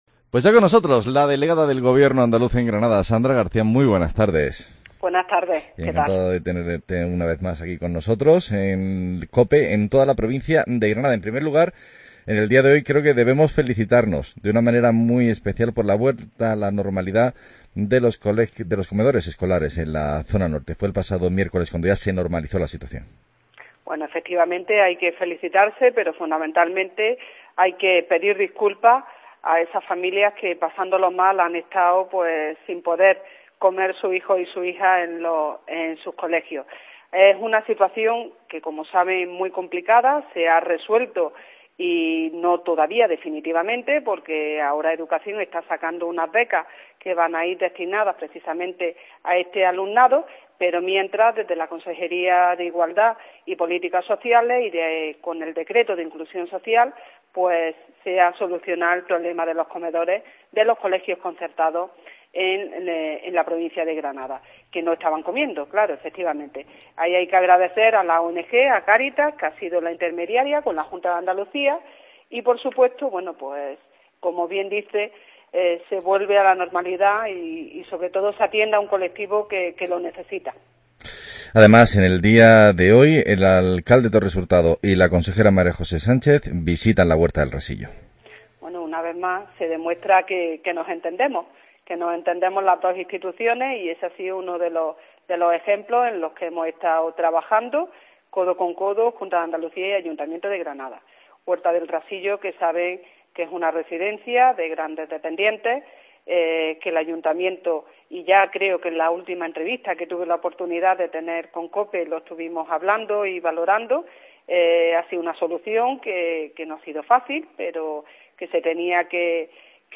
Entrevista a la delegada del Gobierno Sandra Garcia